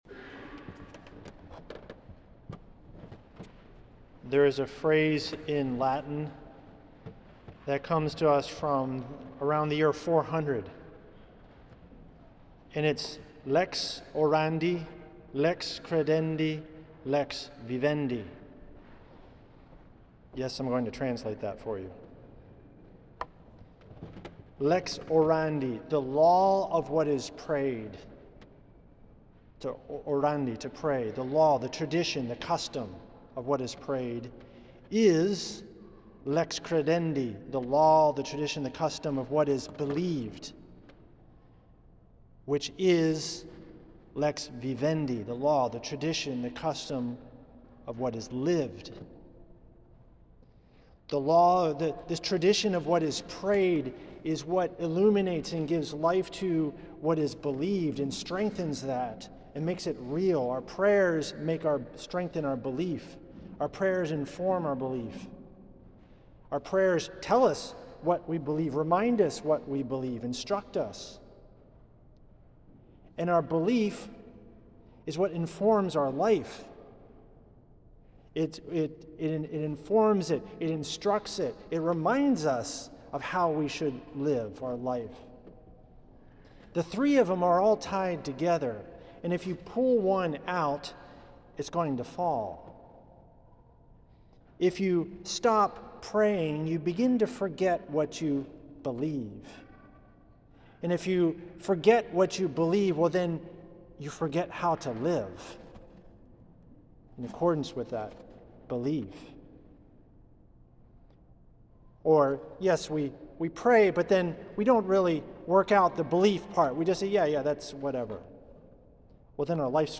a homily
for Palm Sunday, at St. Patrick’s Old Cathedral in NYC.